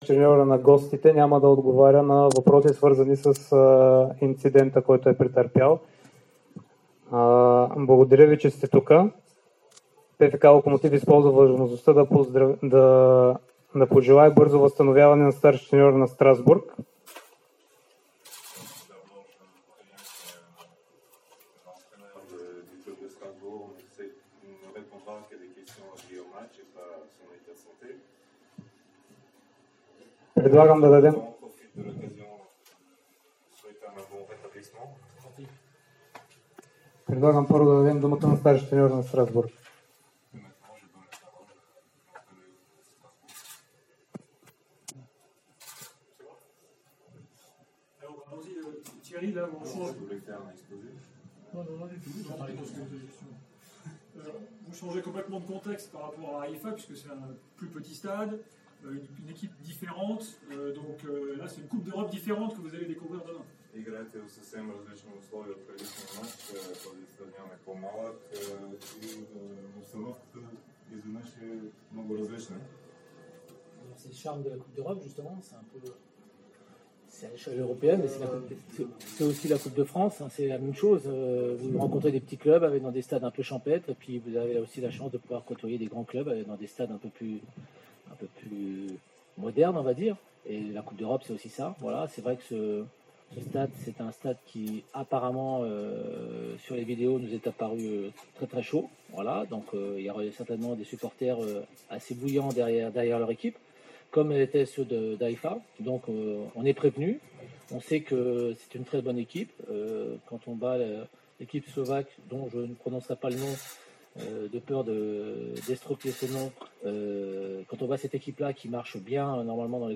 Треньорът на Страсбург Тиери Лори говори пред журналистите преди утрешното гостуване на Локмотив Пловдив от третия предварителен кръг на Лига Европа от 20.30 ч. на "Лаута":